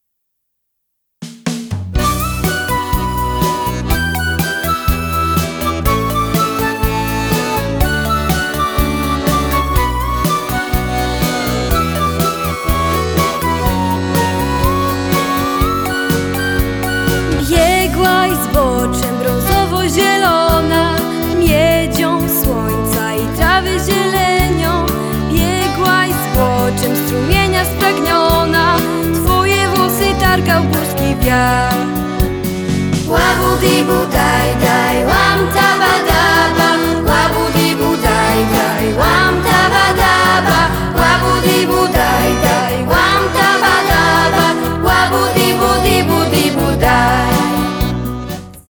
Podkład muzyczny akordeonowy - studyjny.